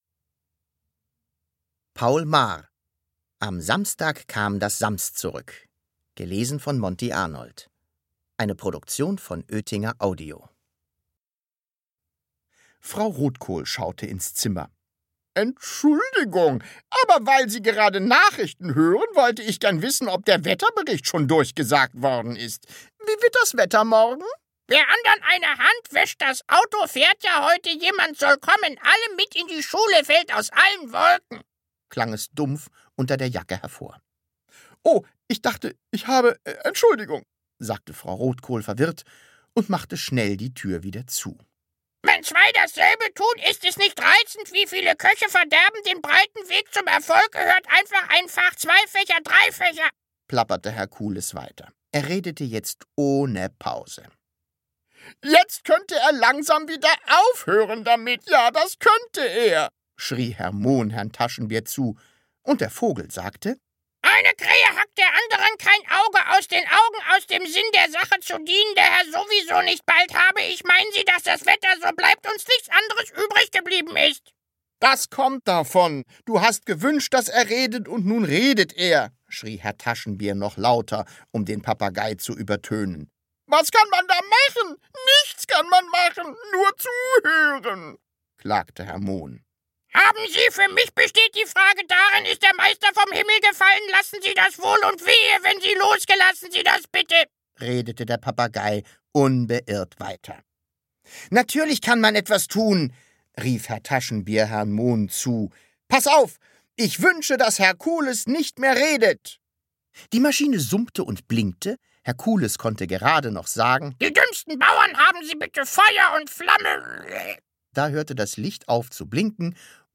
Hörbuch: Das Sams 2.